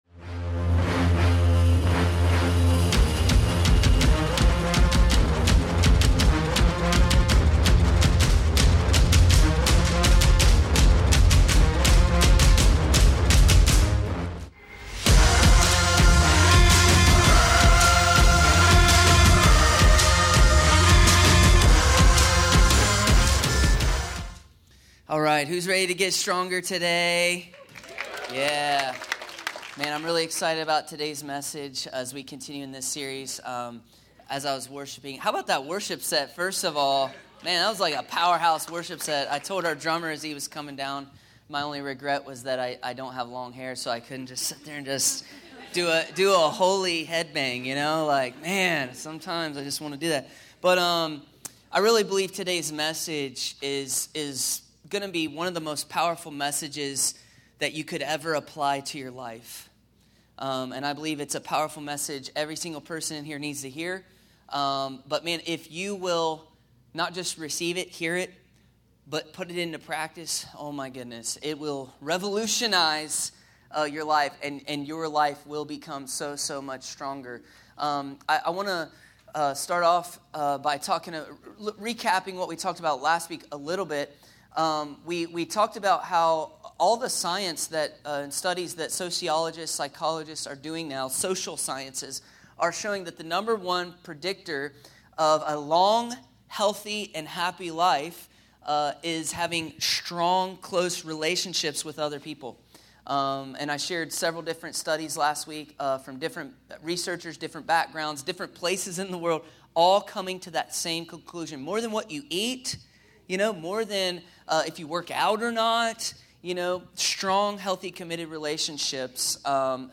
A message from the series “STRONGER.”…